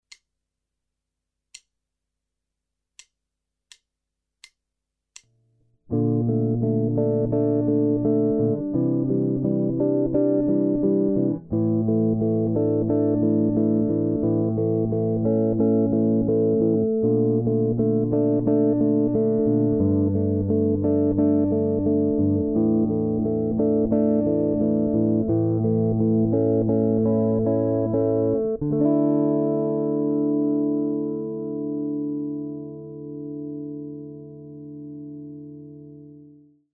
• Stroke type: Free Stroke
• Finger combinations: m+i, p+i
As you can tell, I’ve been a fan of mixing open strings with picking patterns lately.
fingerstyle-tutorial-guitar-fingerpicking-exercises-jazz-ex-3-3.mp3